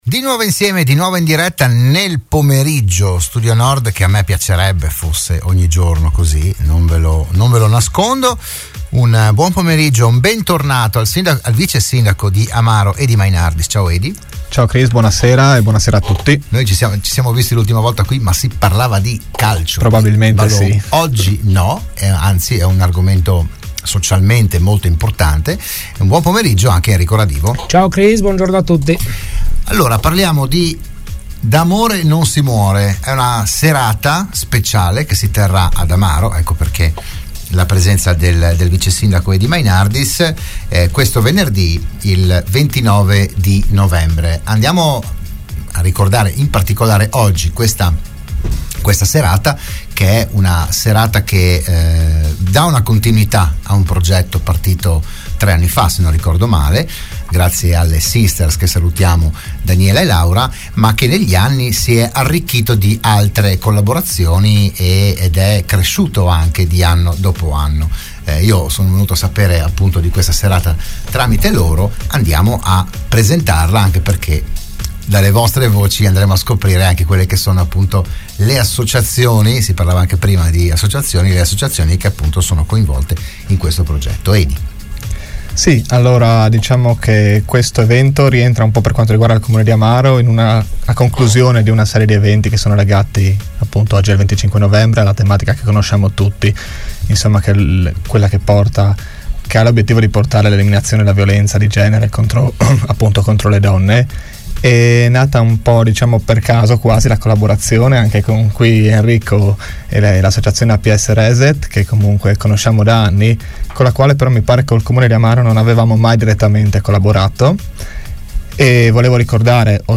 Dell’iniziativa si è parlato a Radio Studio Nord, all’interno di “What if… 2000”